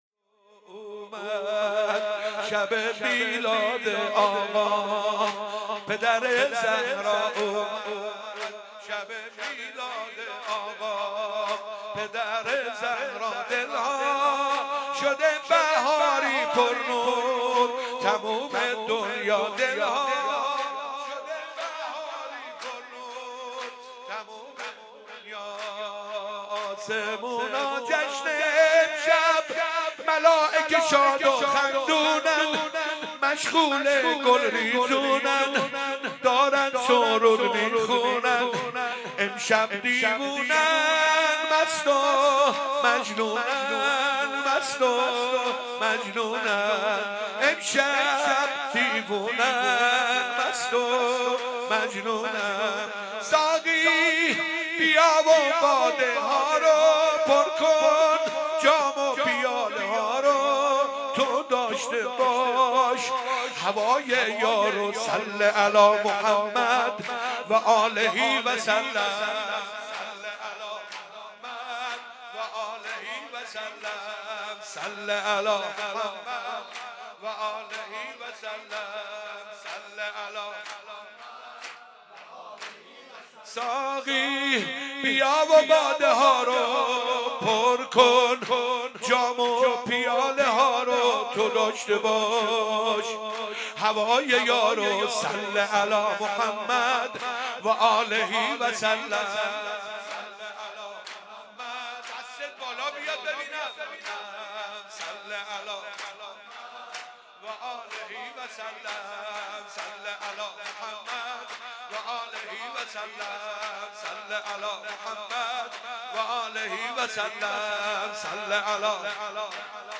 ولادت پیامبر و امام صادق (ع)